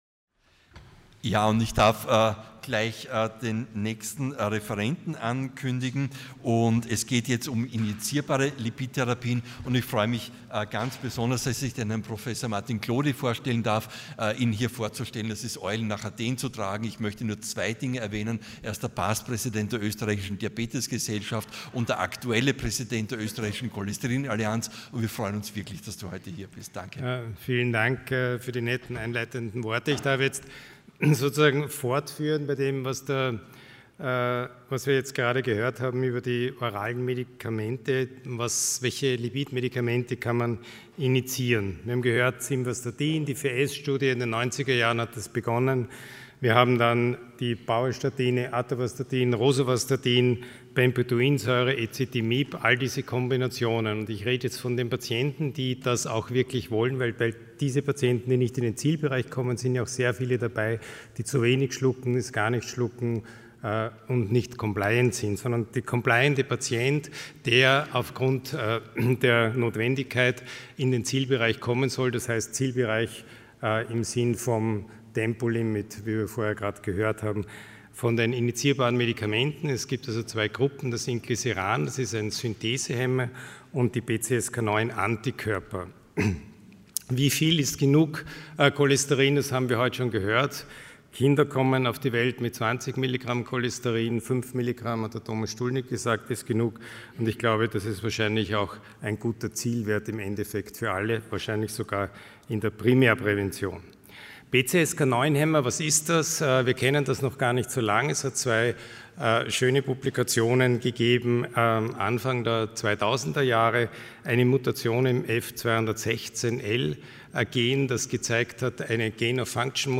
Sie haben den Vortrag noch nicht angesehen oder den Test negativ beendet.
Hybridveranstaltung